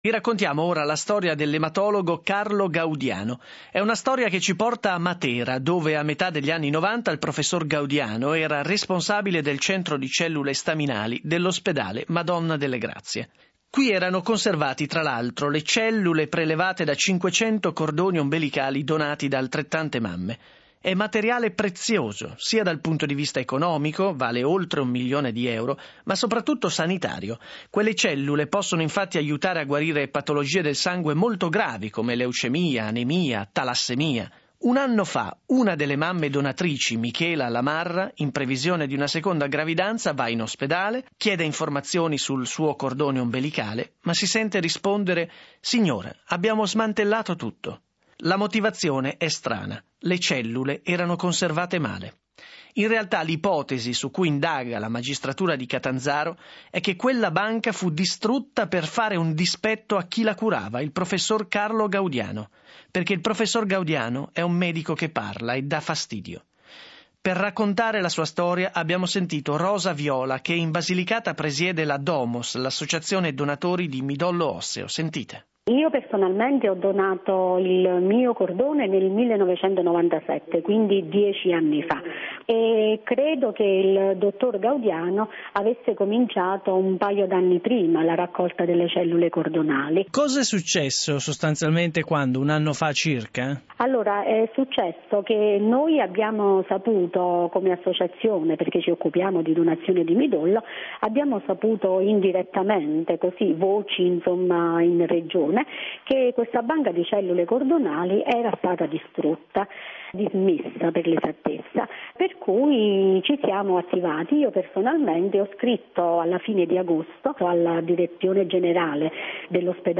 intervista_domos.mp3